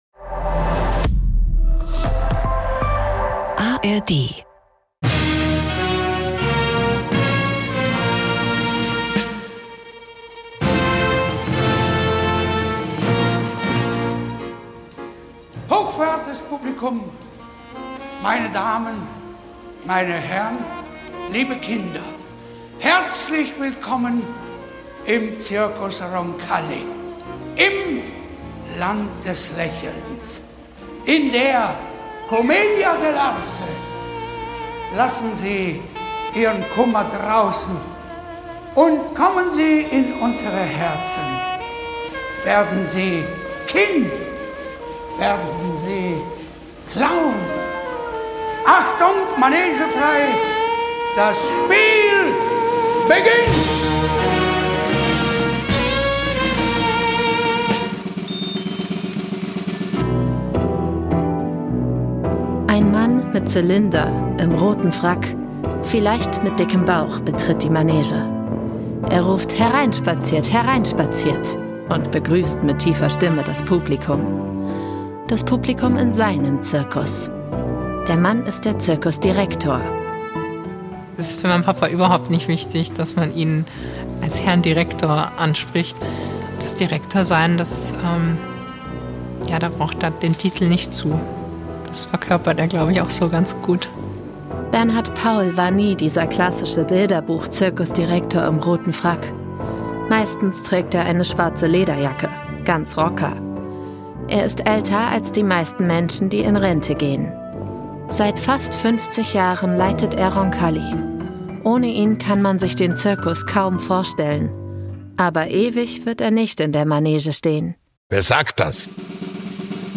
Sprecherin